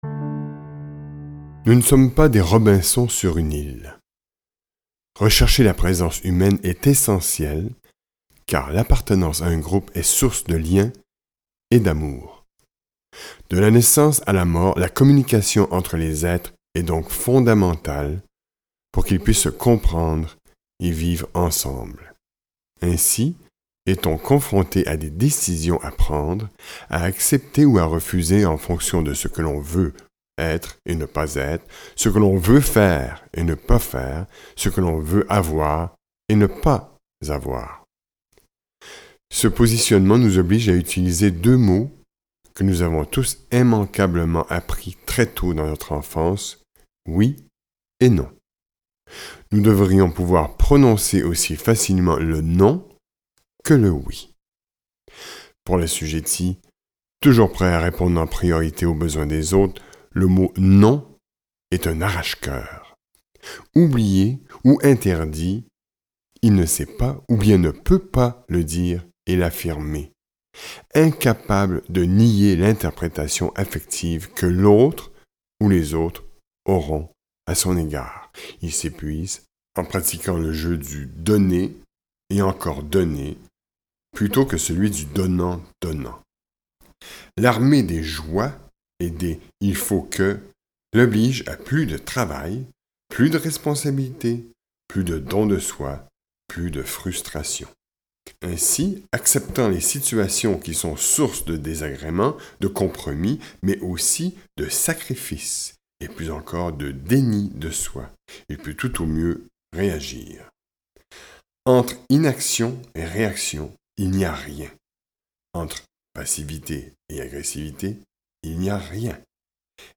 Ce livre audio dresse le profil psychologique inédit de ces personnes qui, par culpabilité, par crainte ou par sentiment de devoir, agissent contre leur propre intérêt.